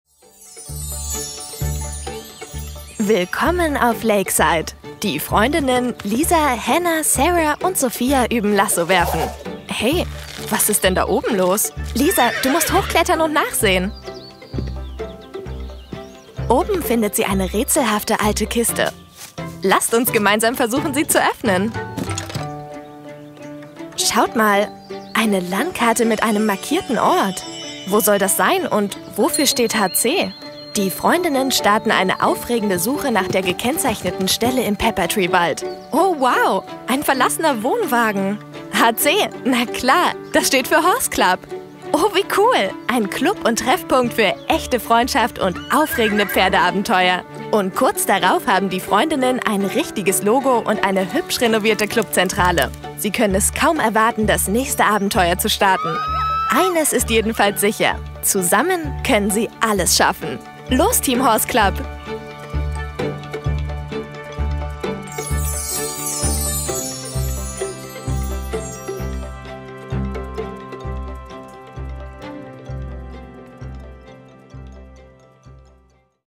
hell, fein, zart
Jung (18-30)
Eigene Sprecherkabine
Children's Voice (Kinderstimme), Narrative, Audiobook (Hörbuch)